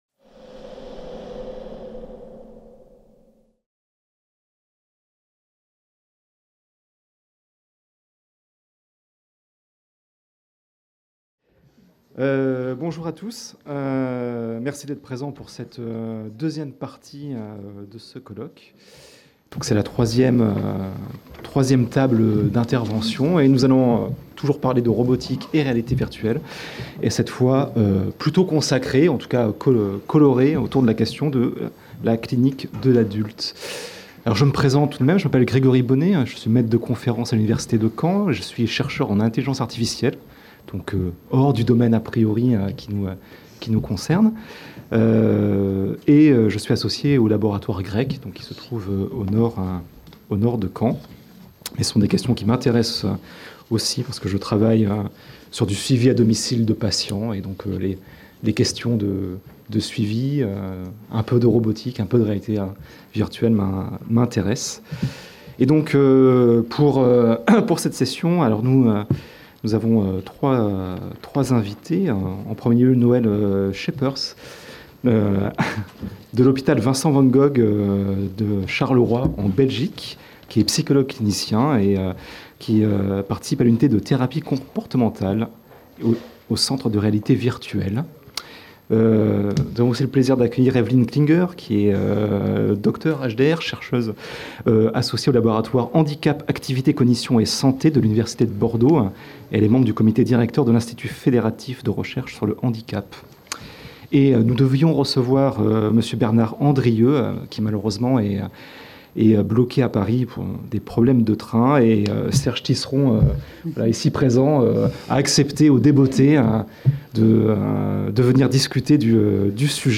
3e table ronde : Robots et RV dans la clinique de l’adulte